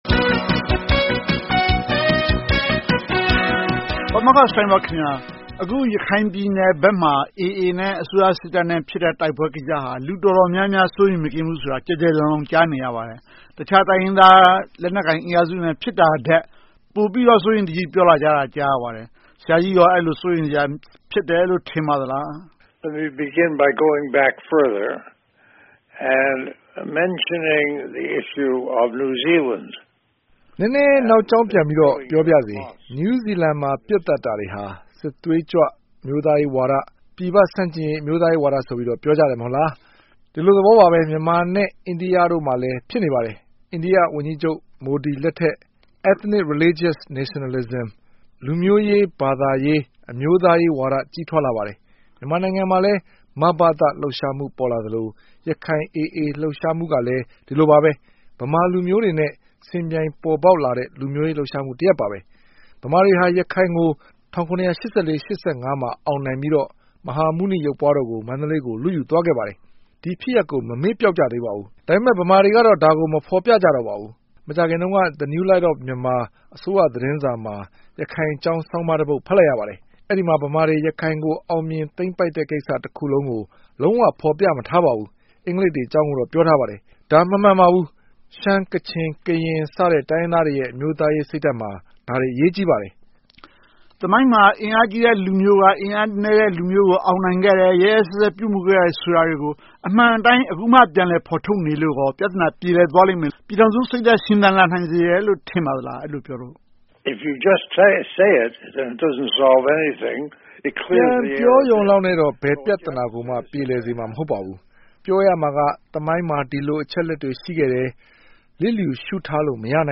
ဆွေးနွေးသုံးသပ်ထားပါတယ်။